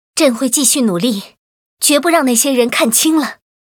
文件 文件历史 文件用途 全域文件用途 Erze_tk_06.ogg （Ogg Vorbis声音文件，长度3.8秒，82 kbps，文件大小：38 KB） 源地址:地下城与勇士游戏语音 文件历史 点击某个日期/时间查看对应时刻的文件。